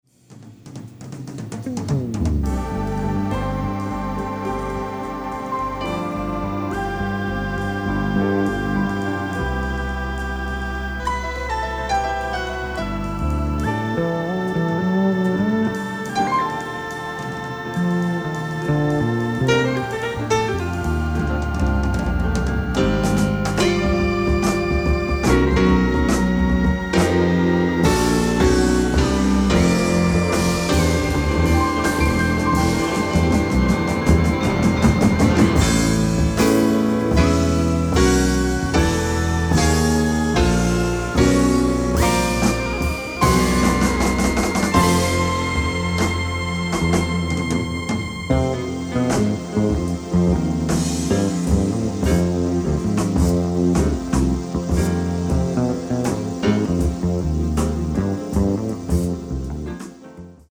piano, fender rhodes, synthesizers
fretless electric bass
drums